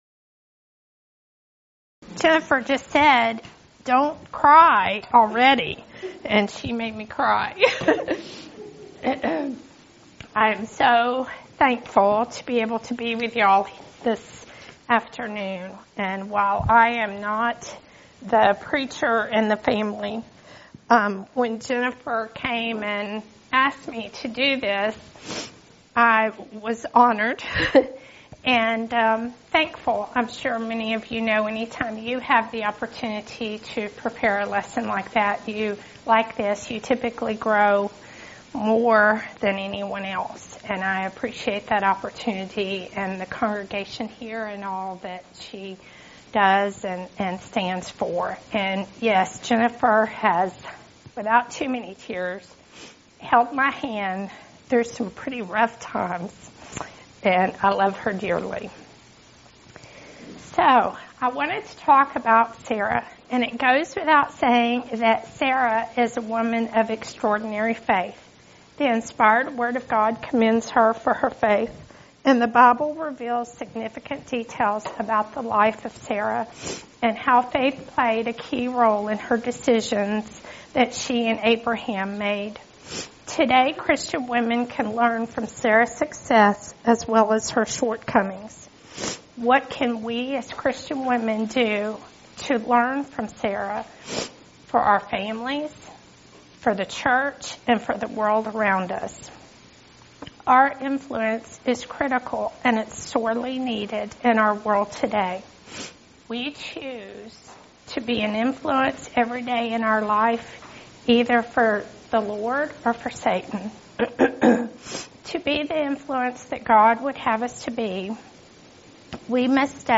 Event: 16th Annual Schertz Lectures
lecture